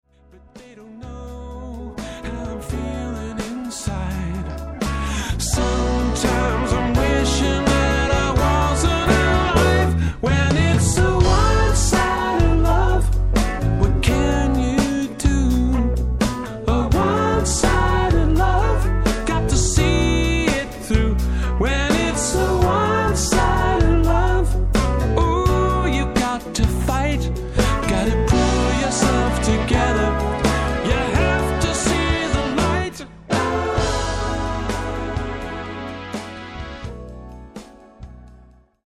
フリー・ソウル・バンド